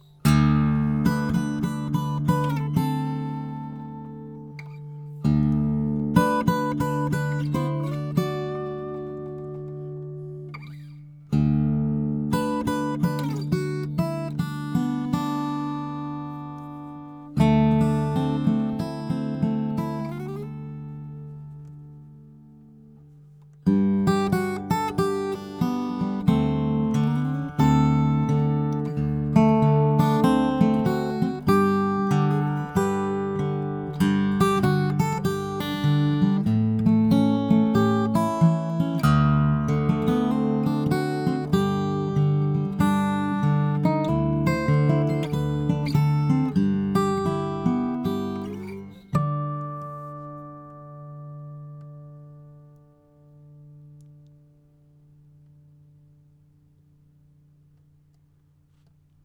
I set up the Lumix GH3 camera and the Rode NT4 mic in front of the guitar stool, tuned up the Wingert to taropatch open G, and played a bit of “Ke Aloha” for the camera and the recorders.
Zoom H6 Music Sample
I hope you’ll listen to the samples yourself and make your own judgment, but I must say that I can hear no difference at all between the preamps in the H6 and the preamps in the RME UFX.
thanks for your test and great guitar playing.
Music-Zoom.wav